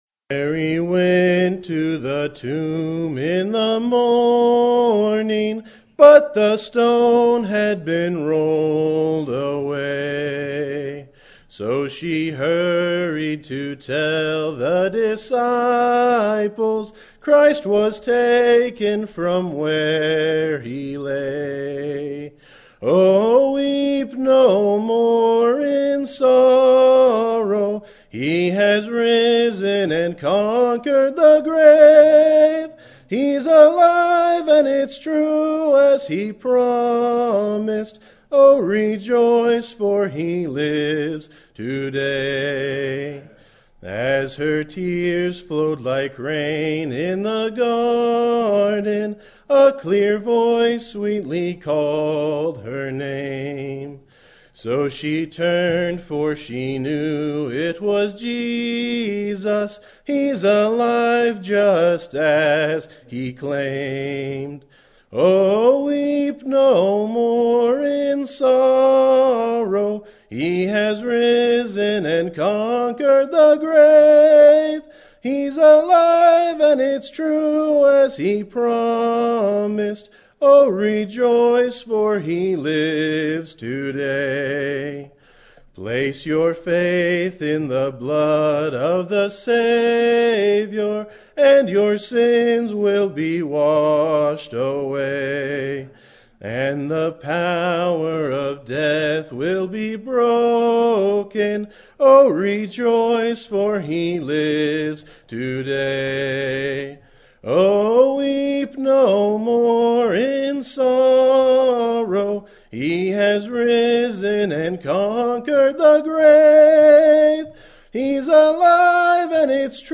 Sermon Topic: General Sermon Type: Service Sermon Audio: Sermon download: Download (27.07 MB) Sermon Tags: Ezra Build Frustrate Cyrus